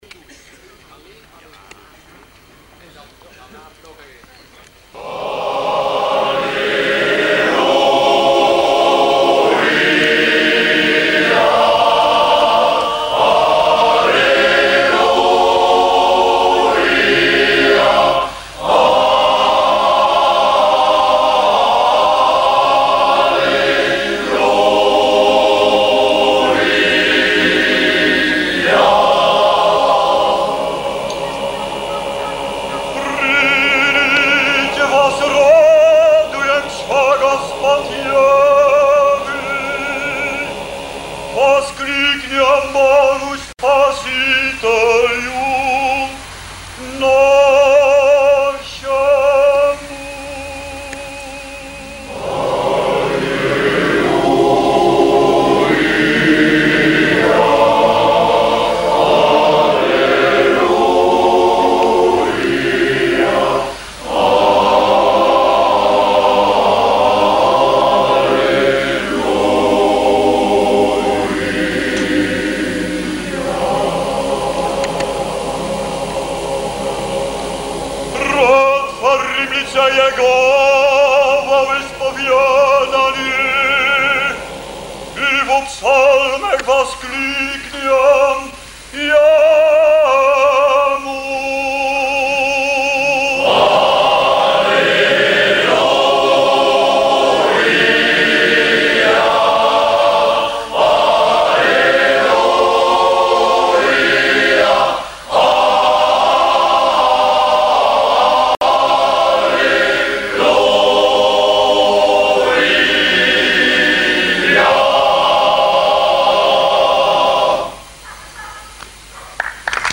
Geleens Mannenkoor Mignon
Alliluia, slavische ritus Partituur Alliluia - Mnôgaja Ljéta gezongen versie Alliluia - Mnôgaja Ljéta life gezongen in Rome 1985 MP3 Alliluia - Mnôgaja Ljéta Video opname Rome 1985 MP4 terug naar Oefenpagina